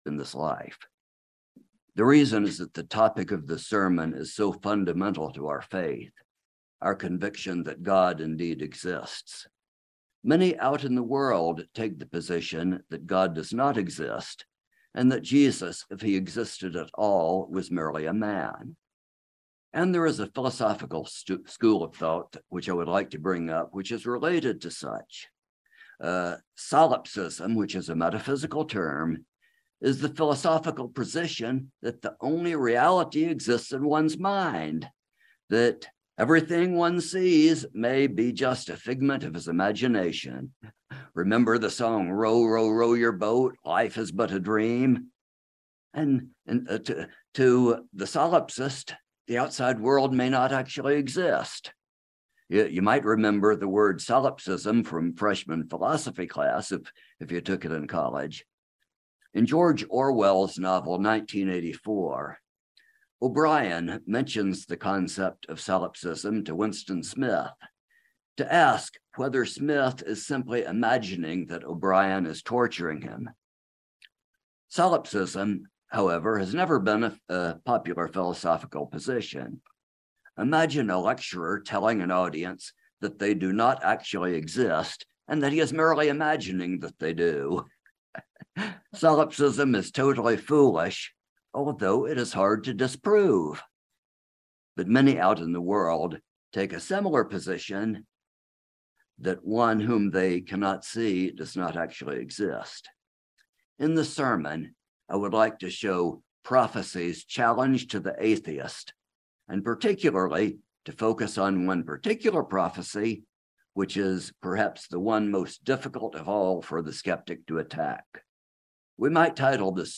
In this sermon I would like to show prophecy’s challenge to the atheist and particularly to focus on one particular prophecy which is perhaps the one most difficult of all for the ske